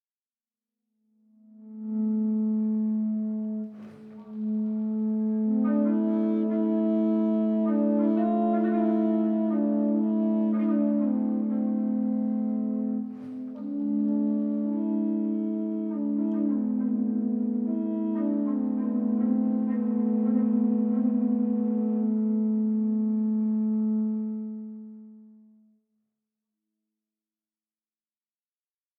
célèbre ensemble vocal
les voix des chanteuses